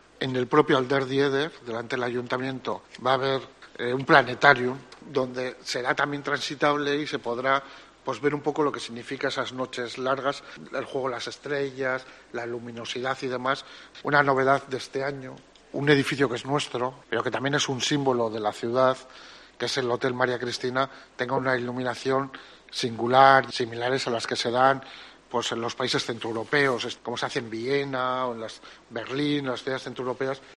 Ernesto Gasco, edill de Impulso Económico